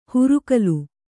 ♪ hurugalu